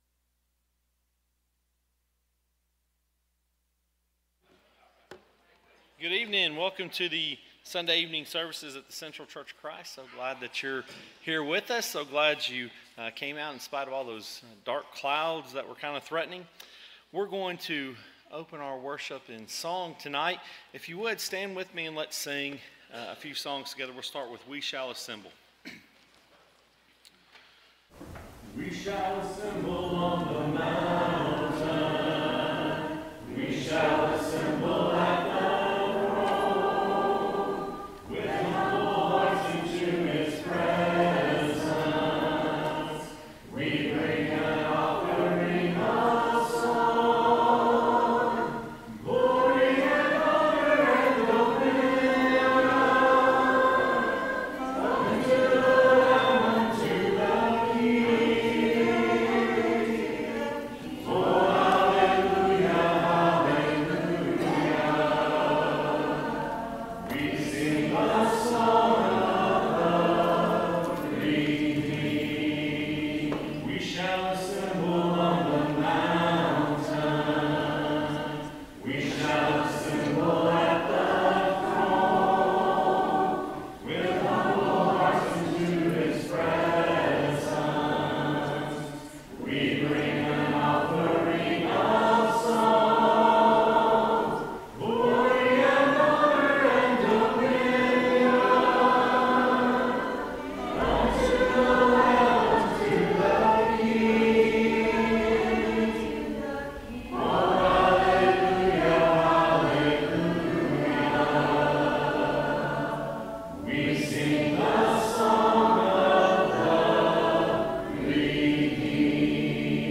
Ephesians 4:30, English Standard Version Series: Sunday PM Service